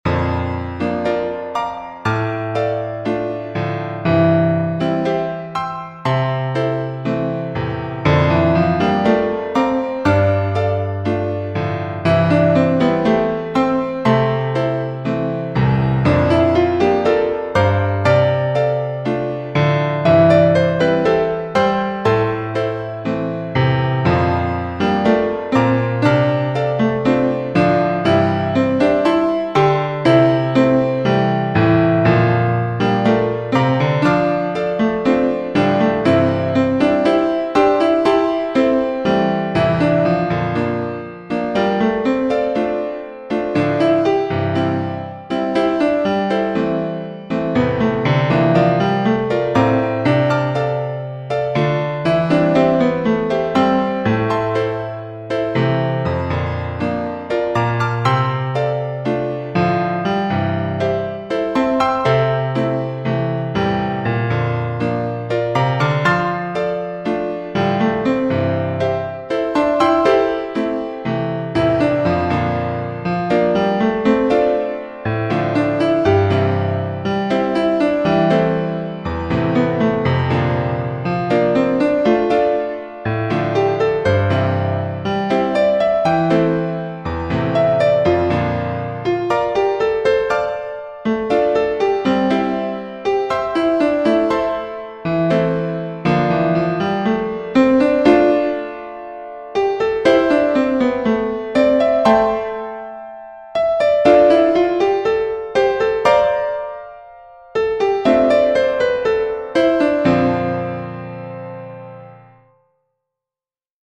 Optimistic (a piece for piano duet)
Wrote this piece recently for two pianos. The melody is quite metronomic and repetitive, almost robotic perhaps?